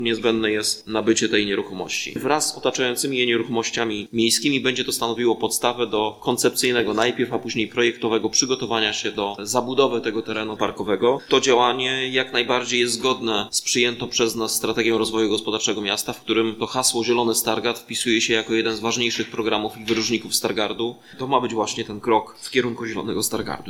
Mówi Prezydent Rafał Zając.